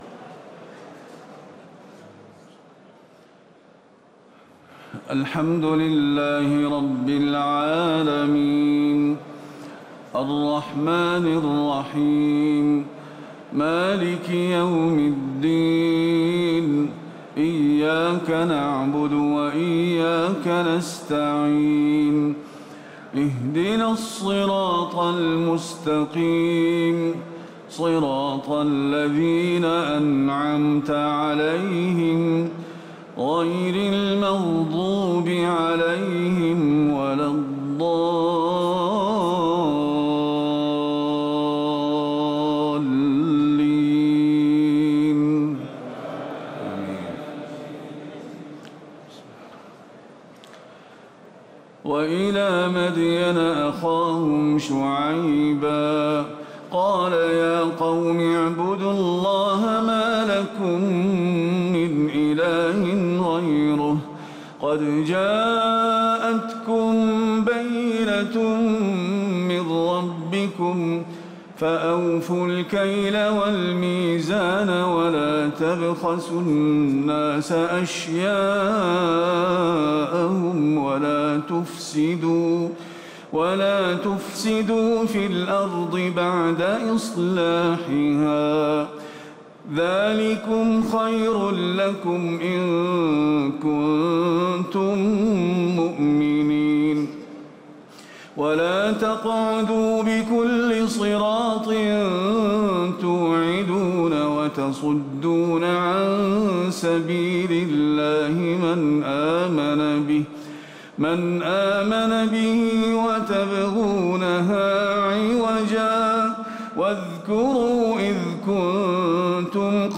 تهجد ليلة 29 رمضان 1438هـ من سورة الأعراف (85-163) Tahajjud 29 st night Ramadan 1438H from Surah Al-A’raf > تراويح الحرم النبوي عام 1438 🕌 > التراويح - تلاوات الحرمين